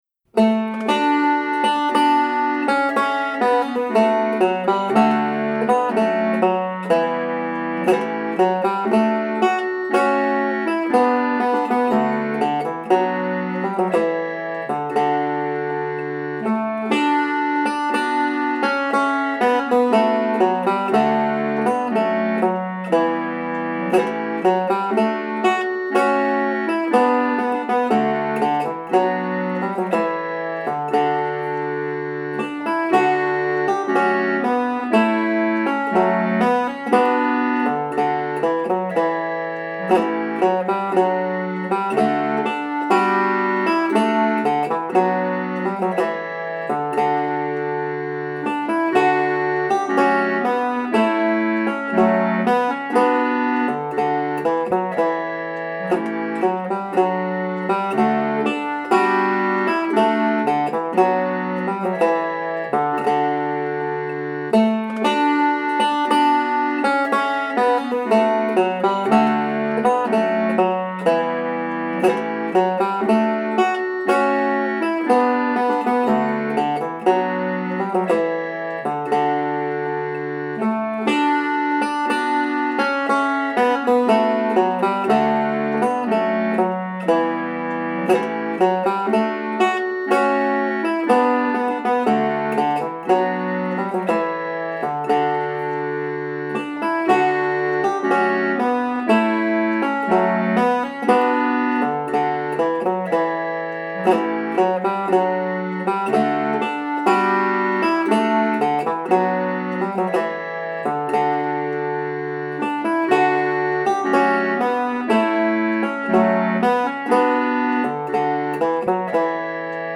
DIGITAL SHEET MUSIC - 5-STRING BANJO
• Welch and Irish, Standard notation and Tab